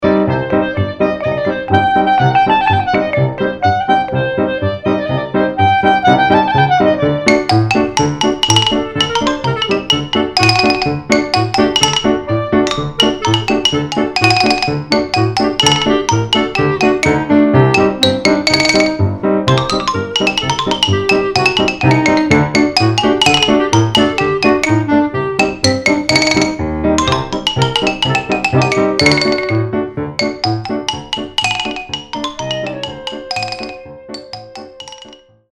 at Jack Straw Studios in Seattle
Though widely played today, this Naftule Brandwein tune, recorded in 1923 is still one of the best examples of klezmer dance music.
shtroyfidl